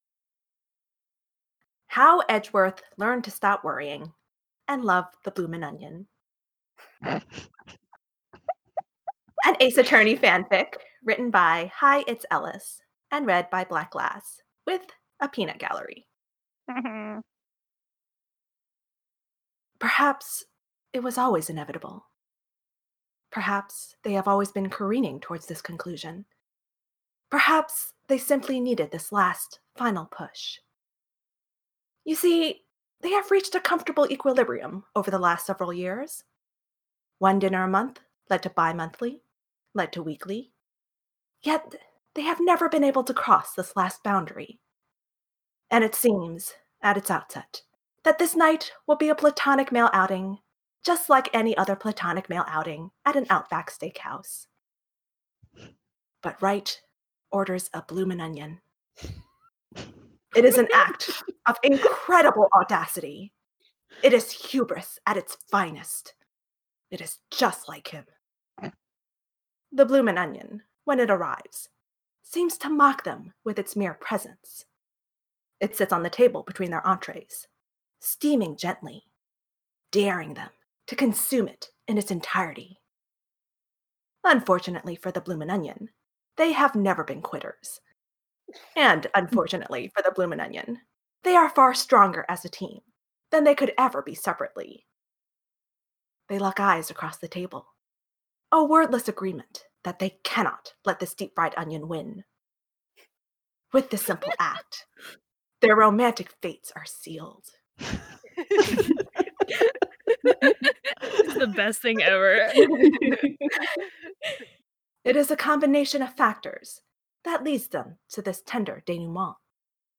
reader